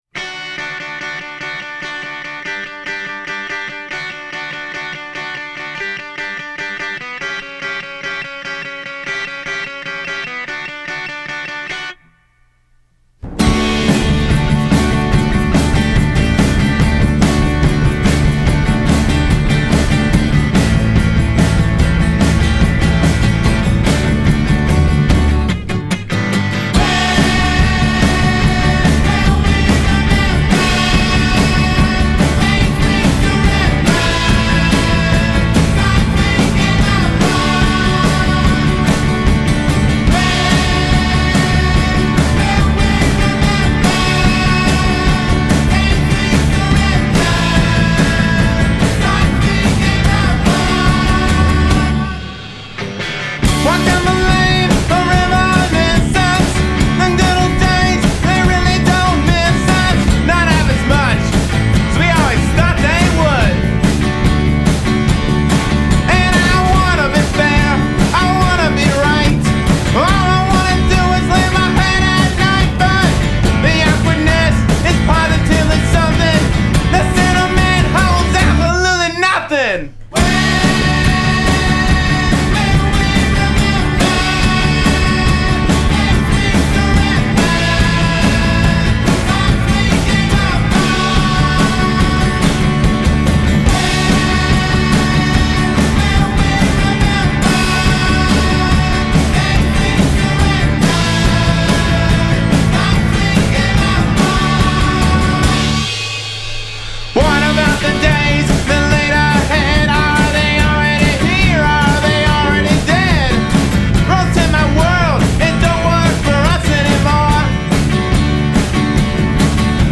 Guitar and Vocals
Bass
Drums
Keyboards